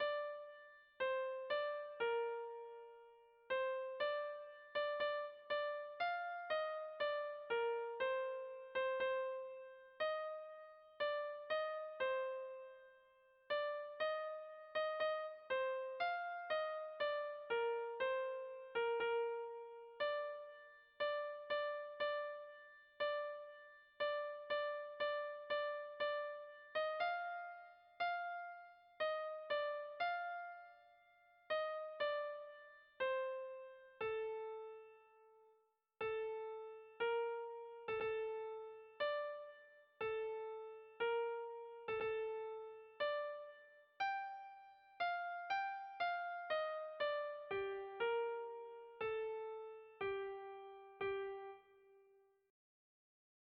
Erlijiozkoa
A-B-C-D-E